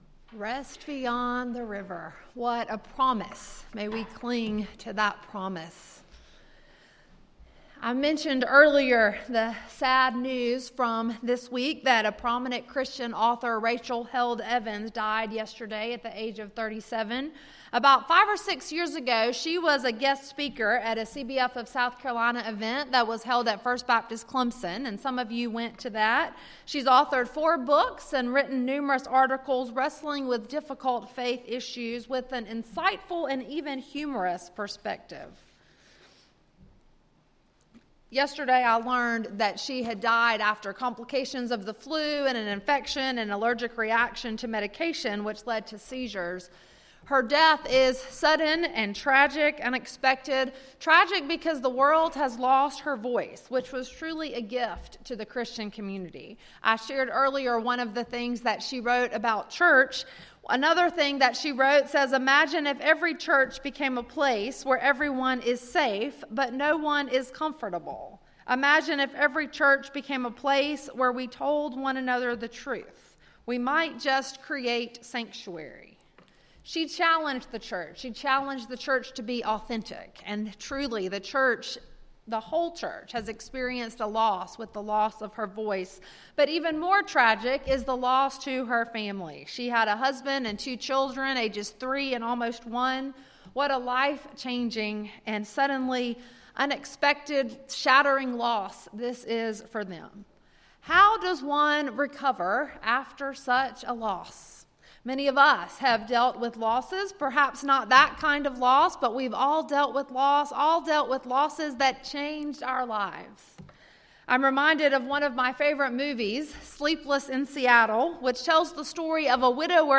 Sermon, Worship Guide, and Announcements for May 5, 2019 - First Baptist Church of Pendleton